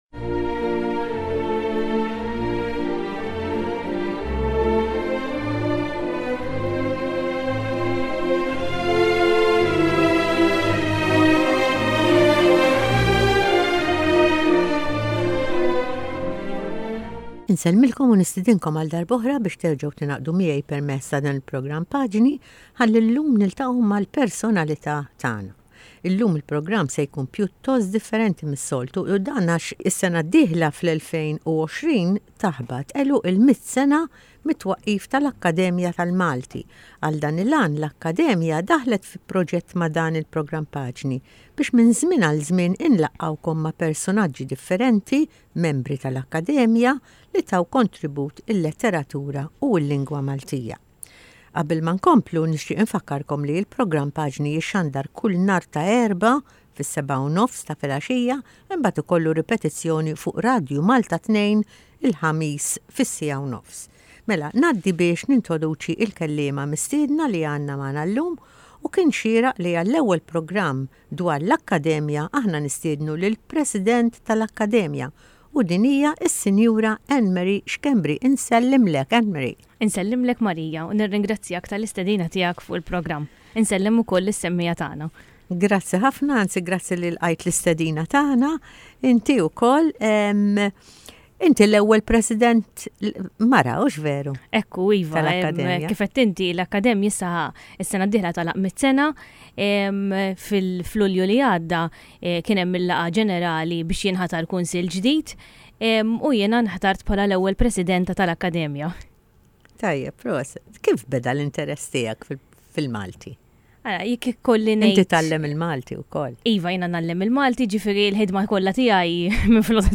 Intervisti